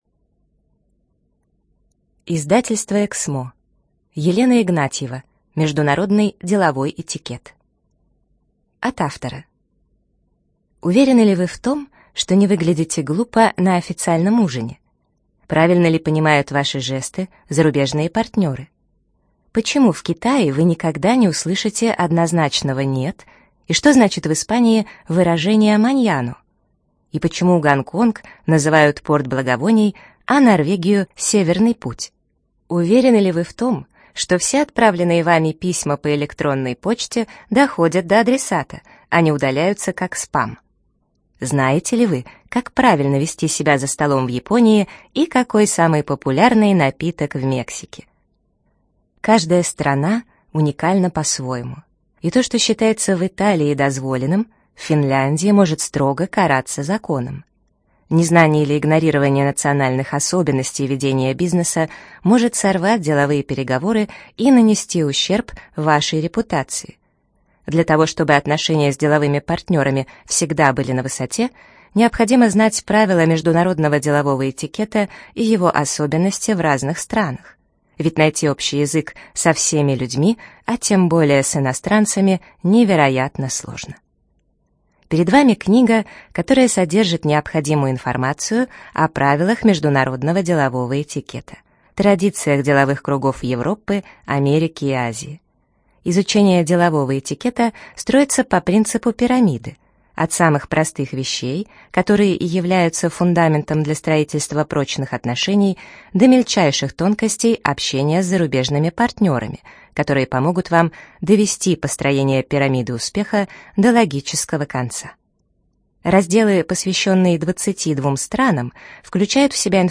ЖанрДеловая литература
Студия звукозаписиЭКСМО